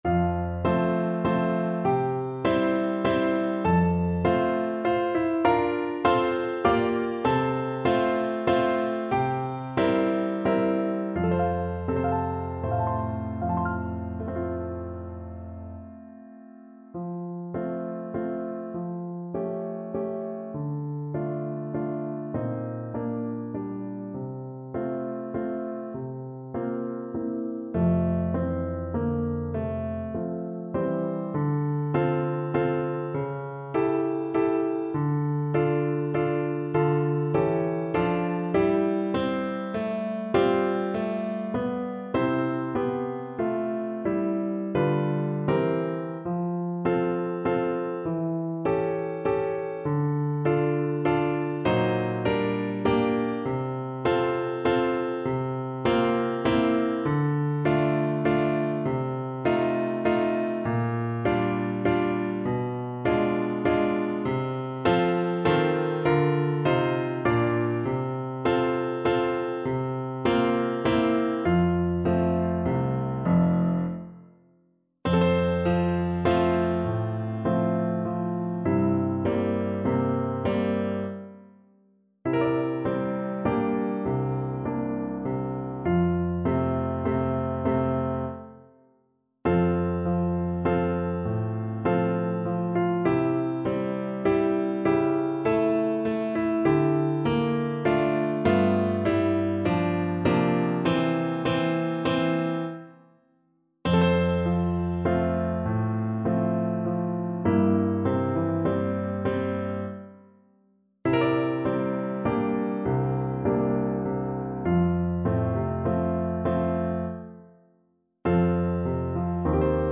Free Sheet music for French Horn
French Horn
F major (Sounding Pitch) C major (French Horn in F) (View more F major Music for French Horn )
3/4 (View more 3/4 Music)
Moderato
Traditional (View more Traditional French Horn Music)